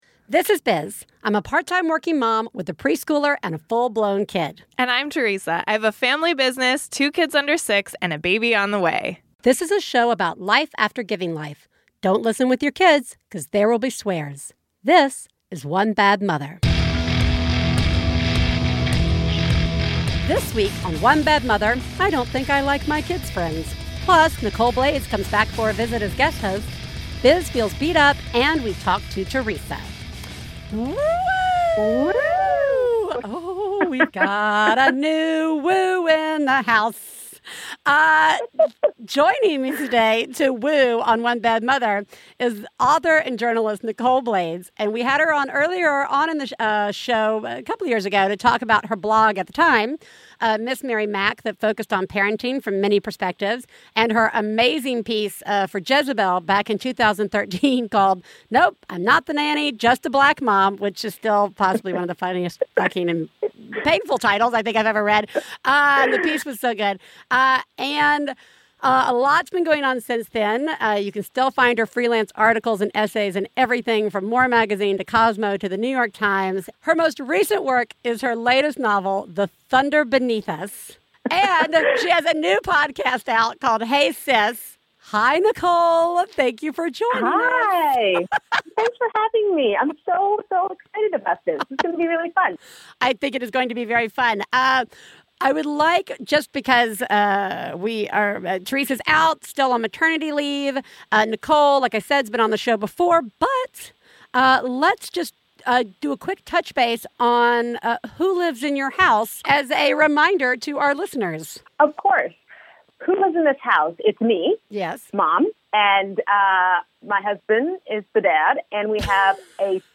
Ep. 194: Do I Have To Like My Kid's Friends? with guest host
Comedy